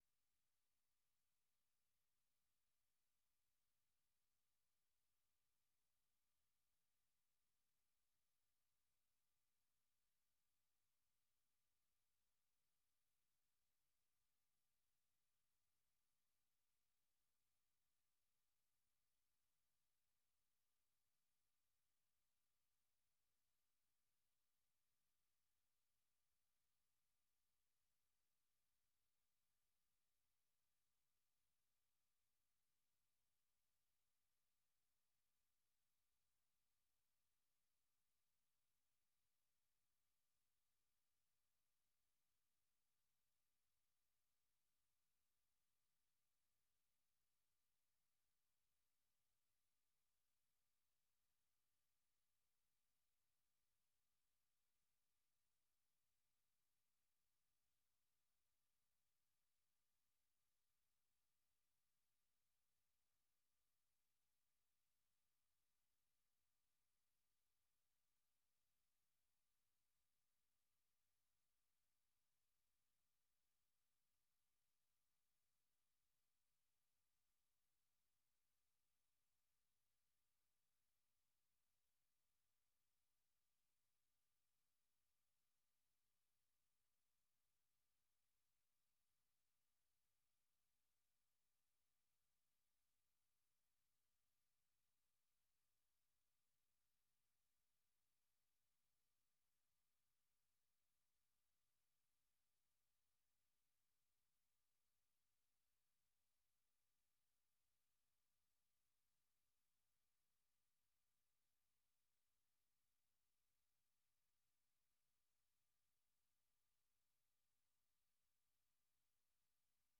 1 Learning English Podcast - June 25, 2025 29:57 Play Pause 11h ago 29:57 Play Pause Play later Play later Lists Like Liked 29:57 Learning English use a limited vocabulary and are read at a slower pace than VOA's other English broadcasts.